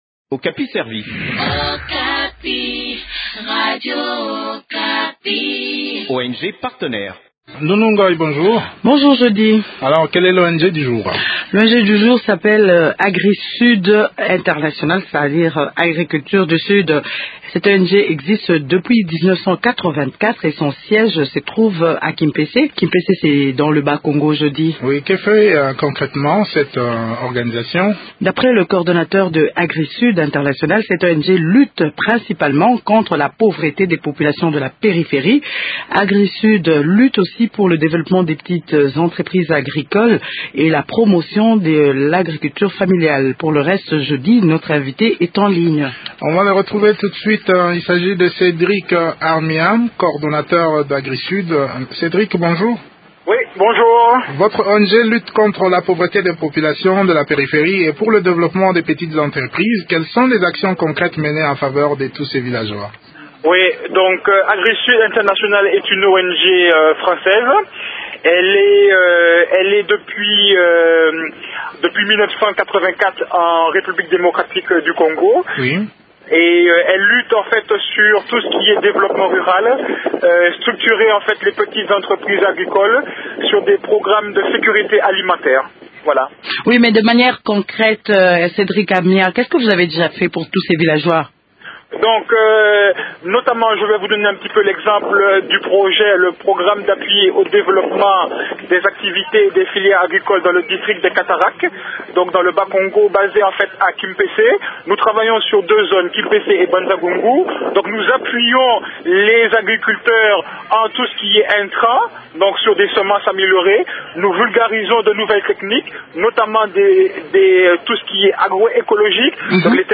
se sont entretenus